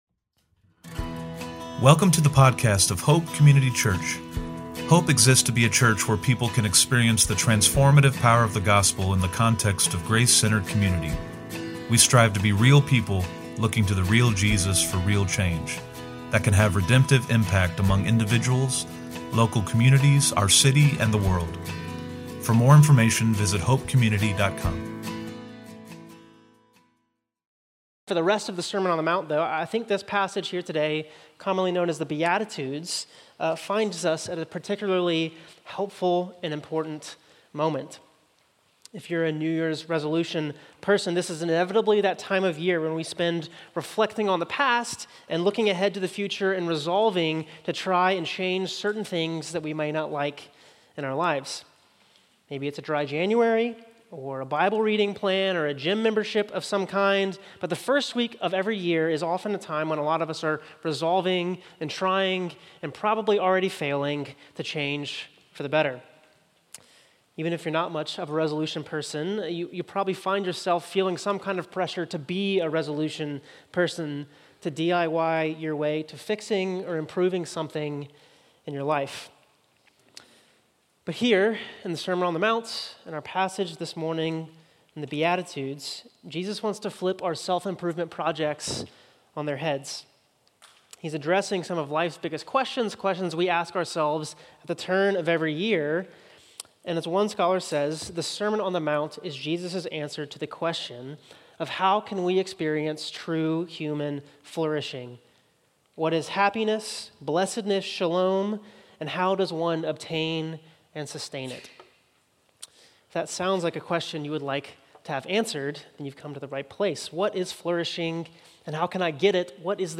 OP-Sermon-1.4.26.mp3